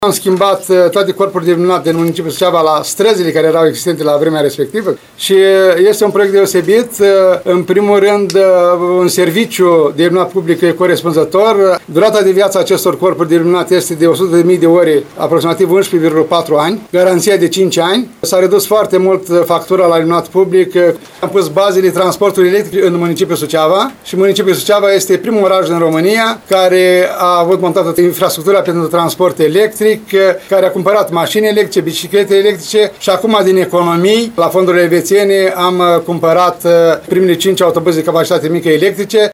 Gazda evenimentului, primarul ION LUNGU, a detaliat proiectele pe fonduri elvețiene de modernizare a iluminatului public și de dotare cu autobuze electrice.